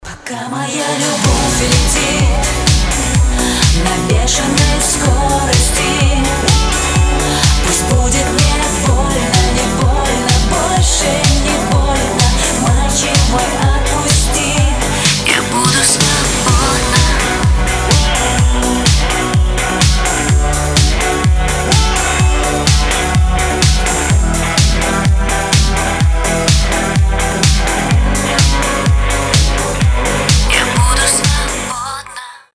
• Качество: 320, Stereo
поп
громкие
женский вокал
электрогитара